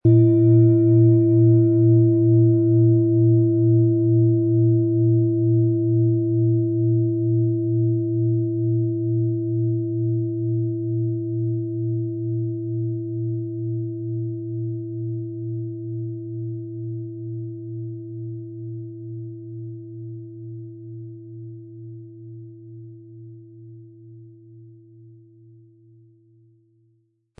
Die Planetenklangschale Mond ist handgefertigt aus Bronze.
• Mittlerer Ton: Hopi-Herzton
• Höchster Ton: Wasserstoffgamma